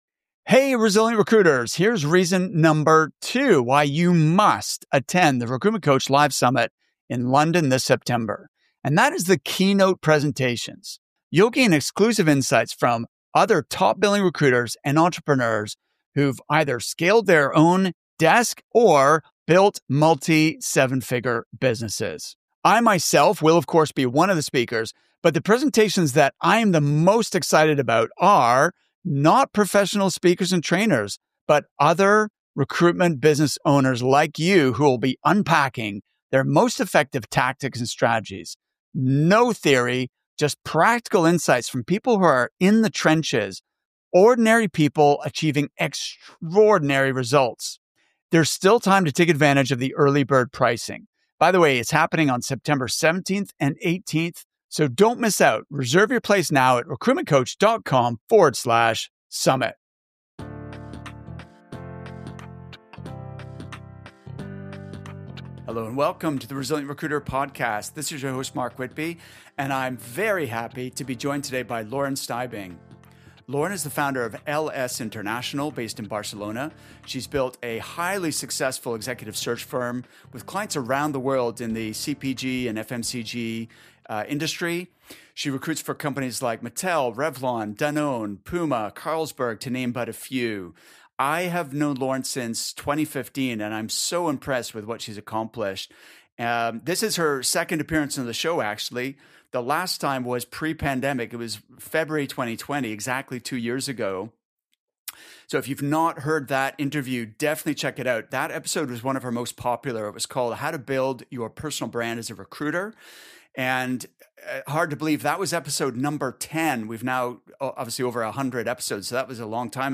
In this interview, my guest…